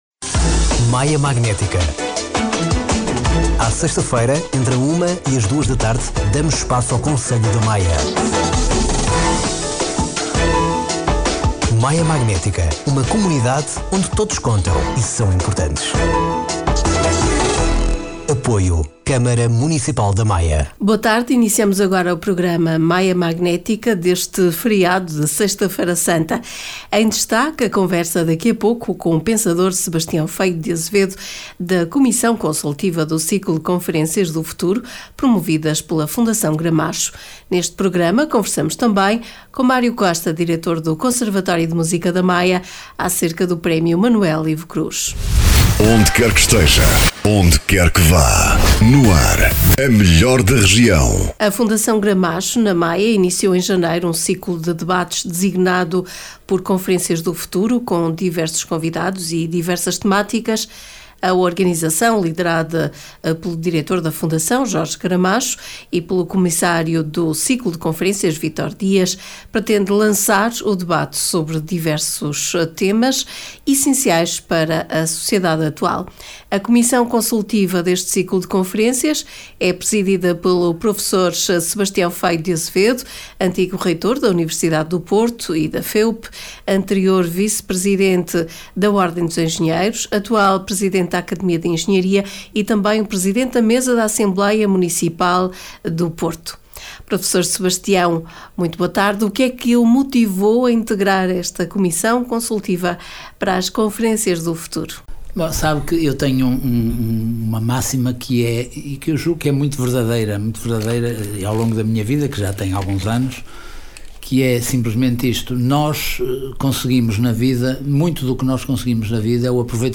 Neste Podcast destacamos a entrevista